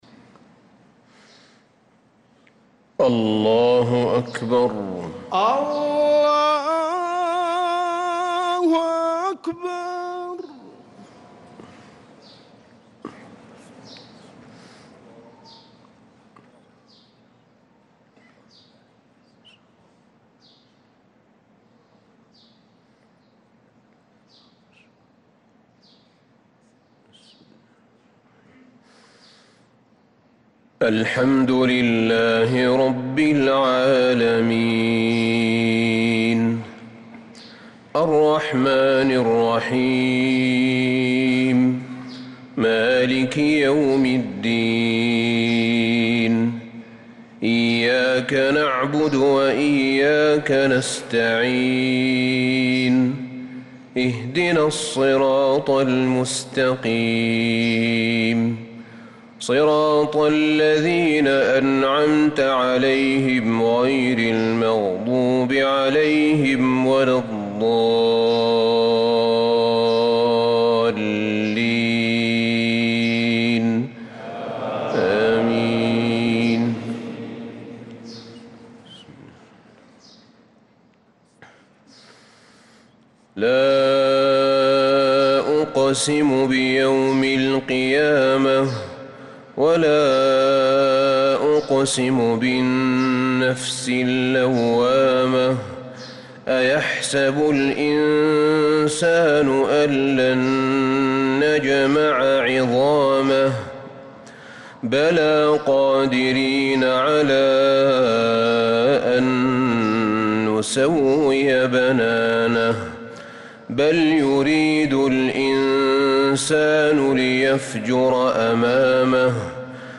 صلاة الفجر للقارئ أحمد بن طالب حميد 9 ربيع الأول 1446 هـ
تِلَاوَات الْحَرَمَيْن .